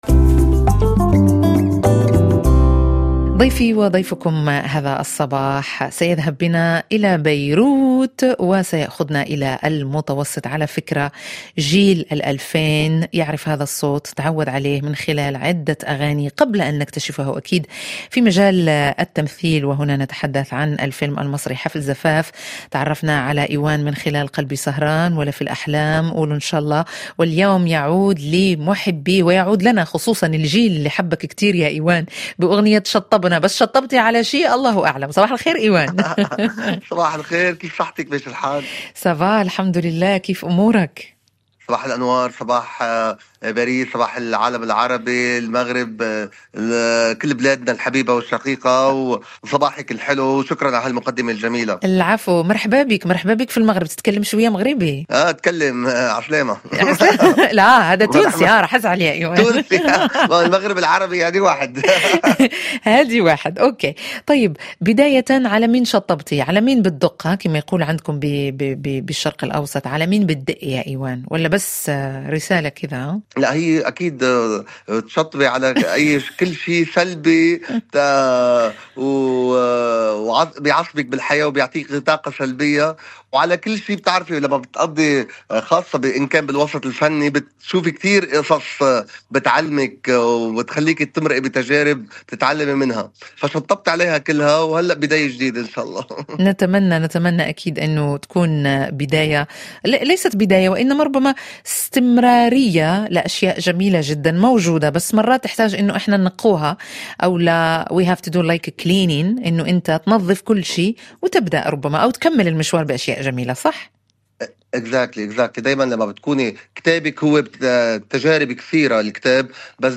مجلة صباحية يومية يلتقي فيها فريق كافيه شو مع المستمعين للتفاعل من خلال لقاءات وفقرات ومواضيع يومية من مجالات مختلفة : ثقافة، فنون، صحة، مجتمع، بالاضافة إلى الشأن الشبابي عبر مختلف بلدان العالم العربي.